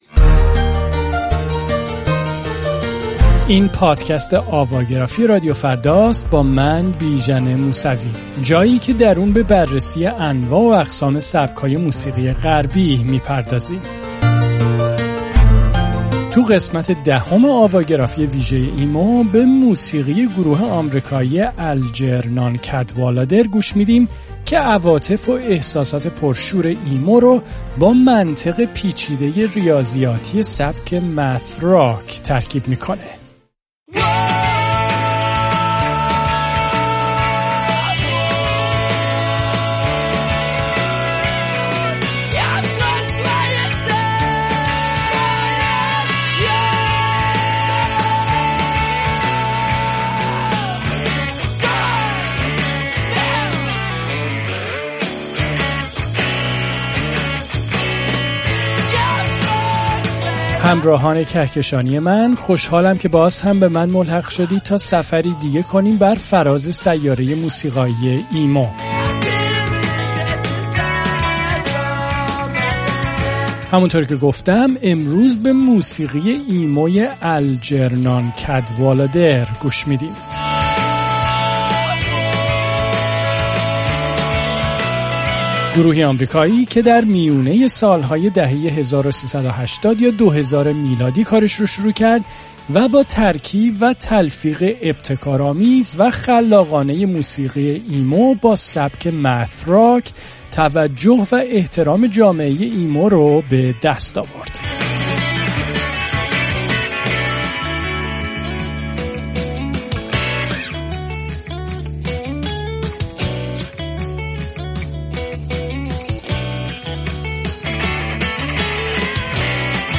گروه « اَلجِرنان کَدوالادِر» وعواطف و احساسات پرشور «ایمو» که با منطق پیچیده‌ ریاضیاتی سبک «مَث راک» در موسیقی آنها ترکیب شده .